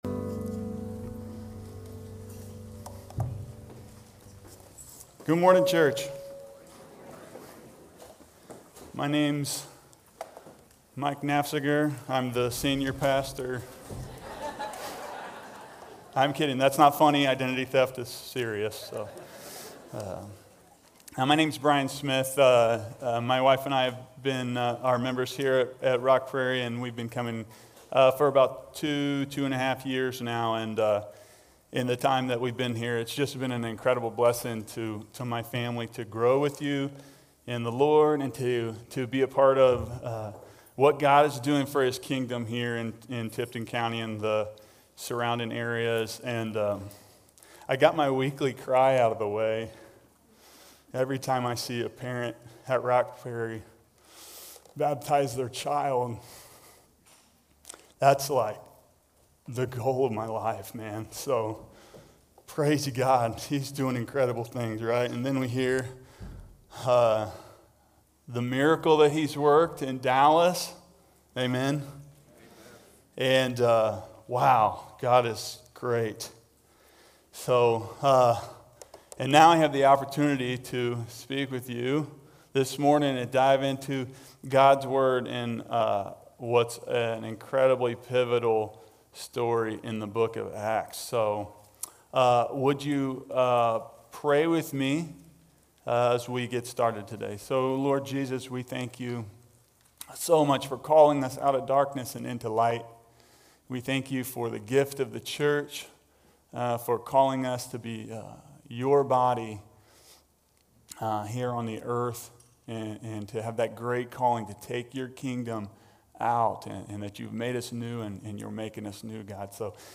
6-30-25-Sunday-Service.mp3